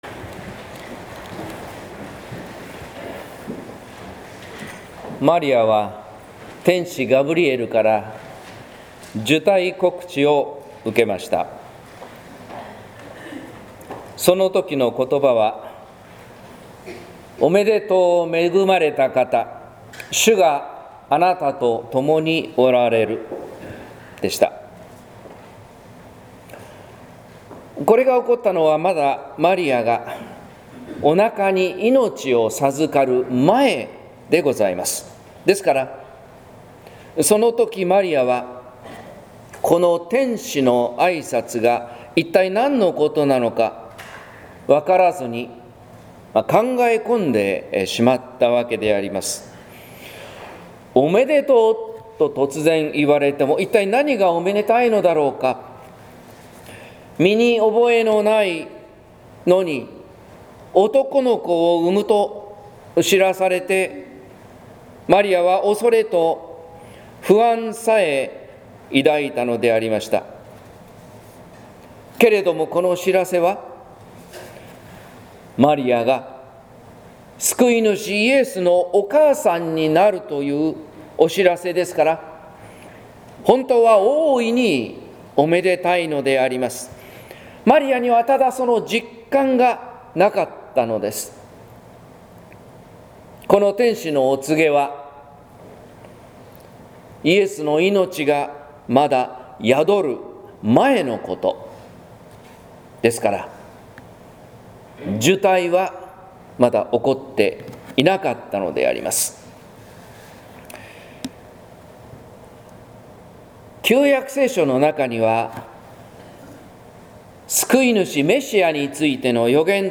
説教「胎に宿る祝福」（音声版）